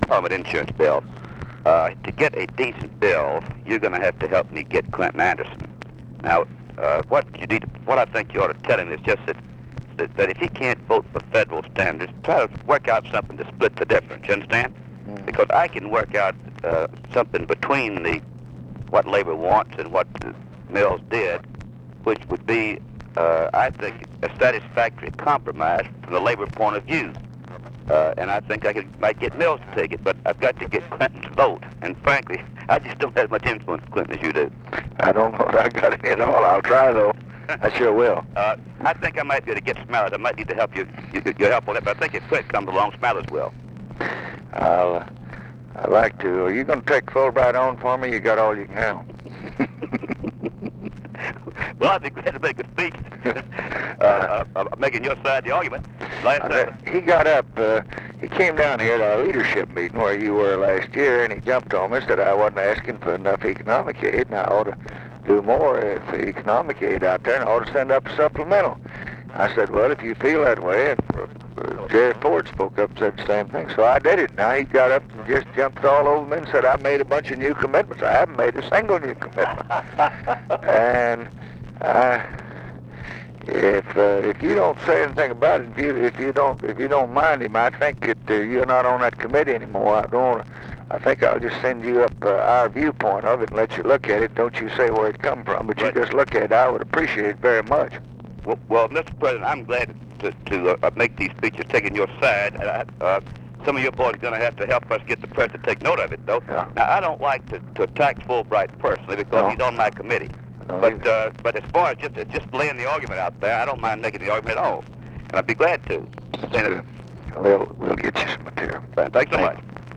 Conversation with RUSSELL LONG, July 23, 1966
Secret White House Tapes